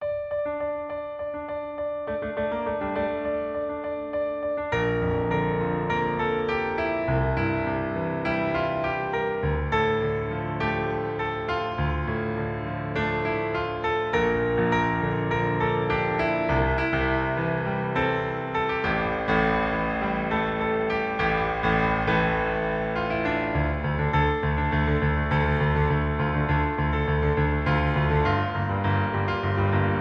• 🎹 Instrument: Piano Solo
• 🎼 Key: D Minor
• 🎶 Genre: Pop
dynamic piano solo arrangement